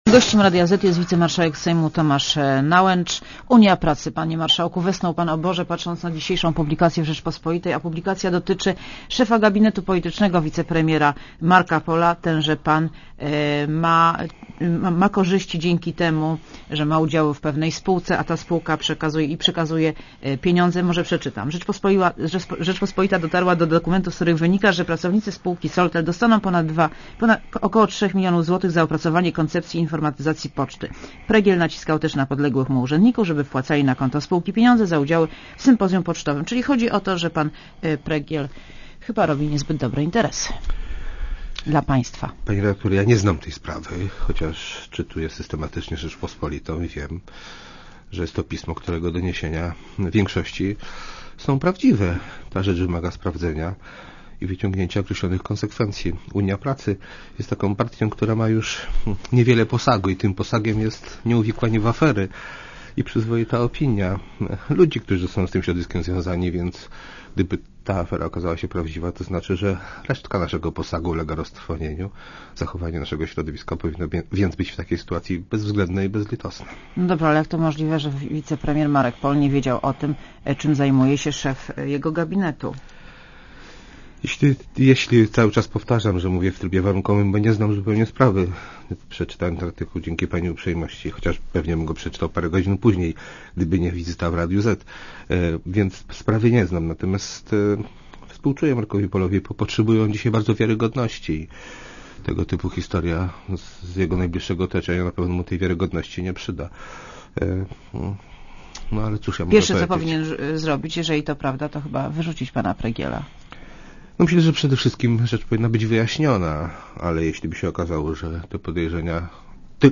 Gościem Radia Zet jest Tomasz Nałęcz, wicemarszałek Sejmu.